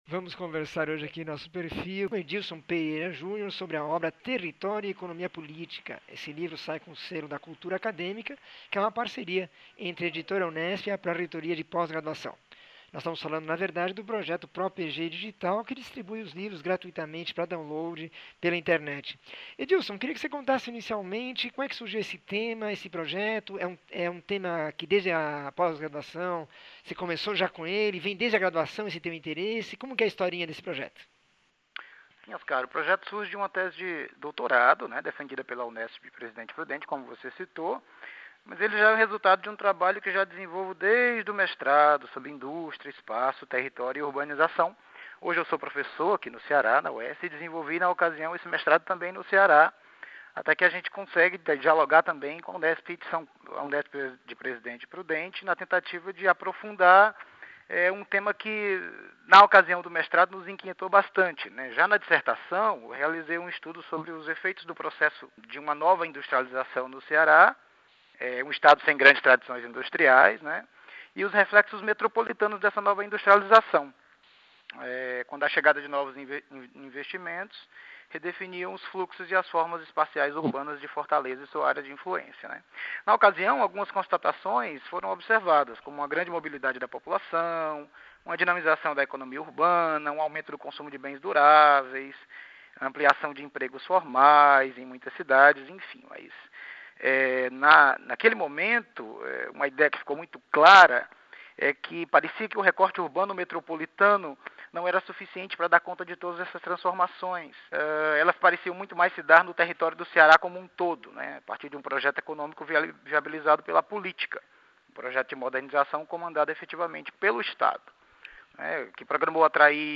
entrevista 1812